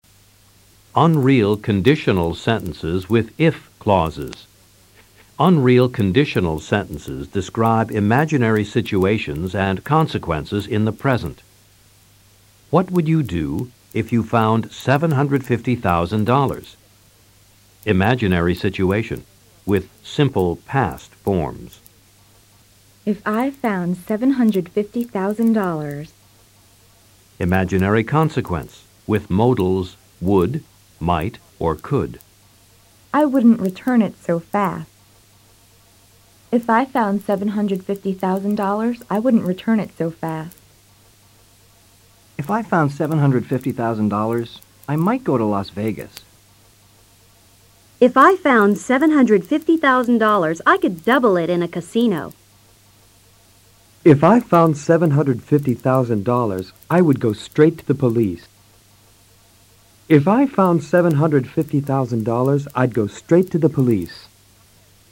Escucha a los profesores y presta atención a la formación del SEGUNDO CONDICIONAL.